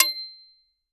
IMPACT_Metal_Pipe_Hits_Pipe_mono.wav